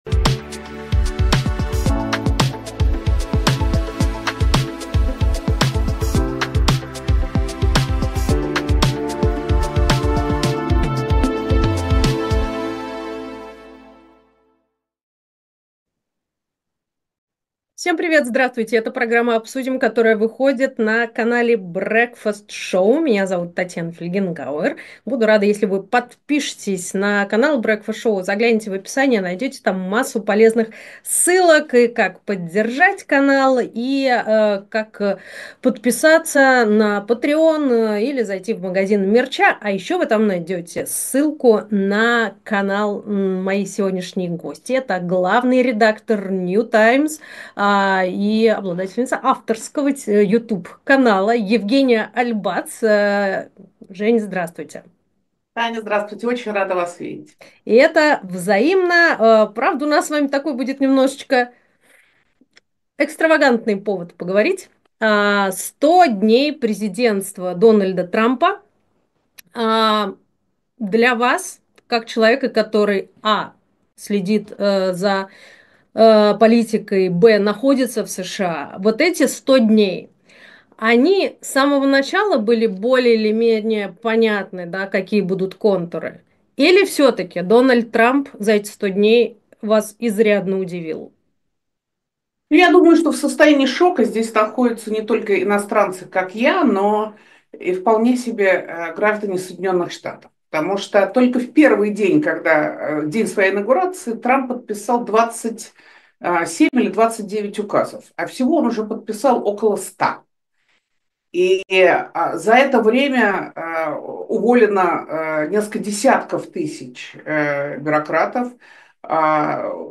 Эфир ведёт Татьяна Фельгенгауэр
В программе «Обсудим» ведущие The Breakfast Show говорят о самых важных событиях вместе с приглашёнными гостями.